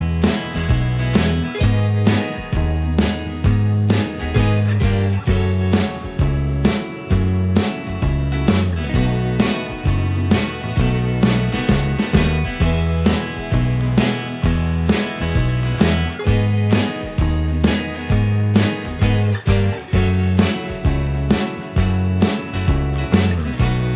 Minus All Guitars Indie / Alternative 3:32 Buy £1.50